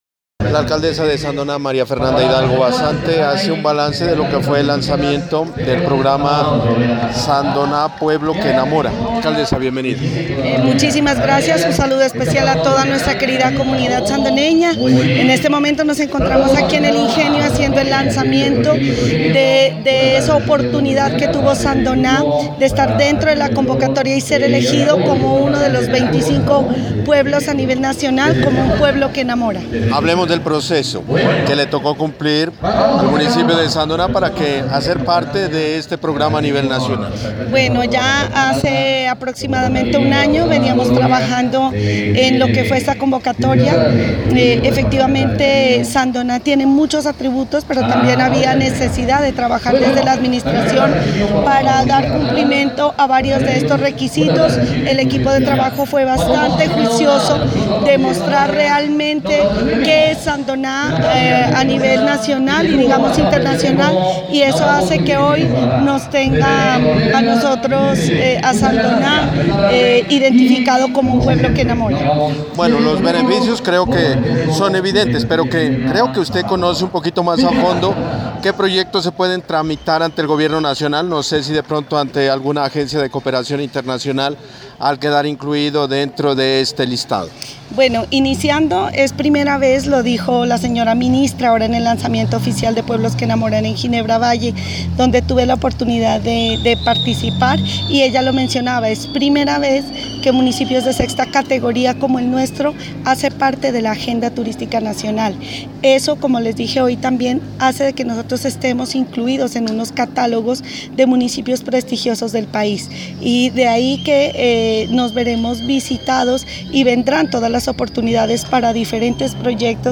Al final dialogamos con la alcaldesa de Sandoná.
Entrevista-alcaldesa.mp3